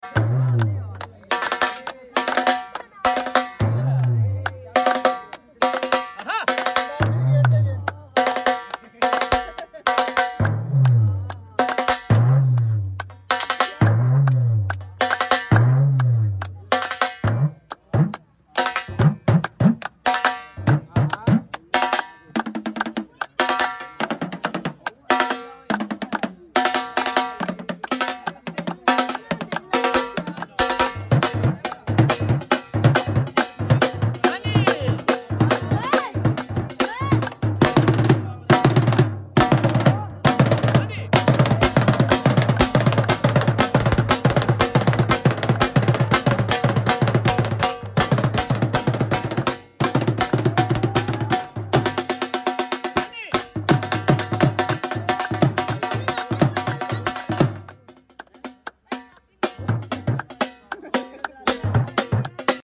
beautiful Dhol beat